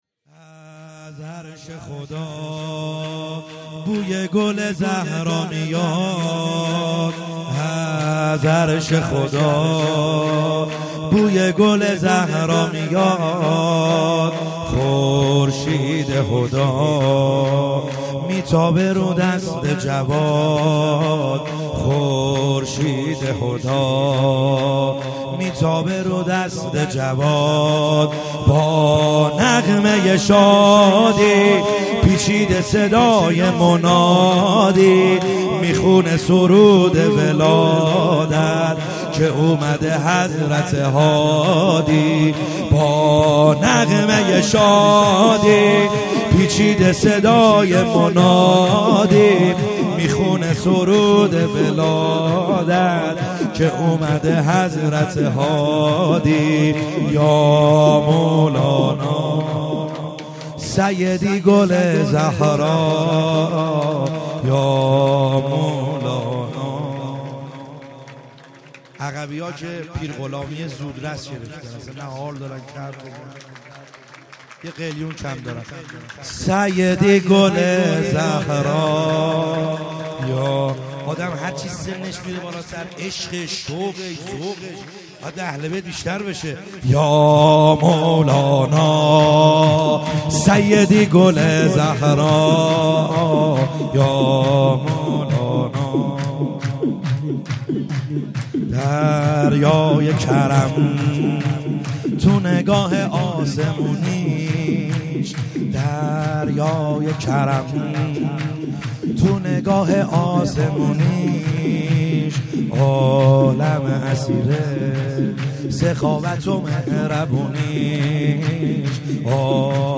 خیمه گاه - بیرق معظم محبین حضرت صاحب الزمان(عج) - سرود | از عرش خدا